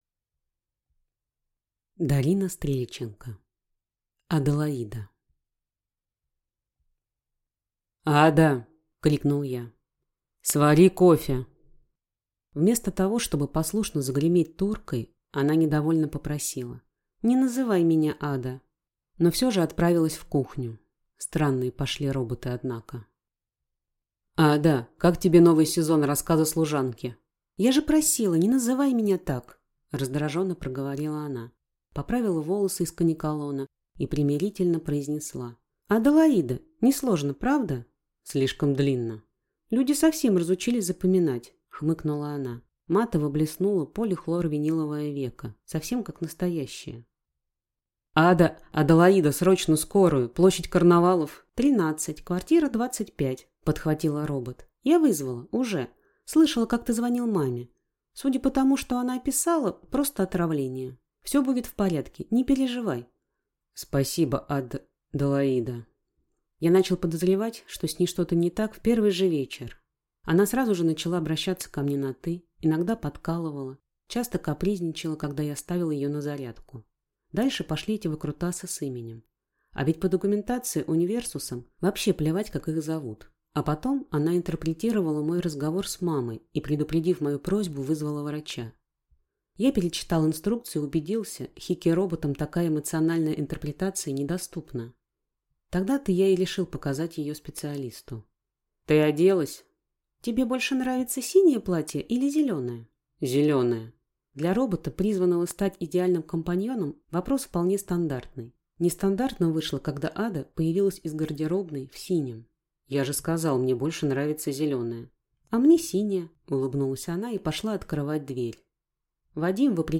Аудиокнига Аделаида | Библиотека аудиокниг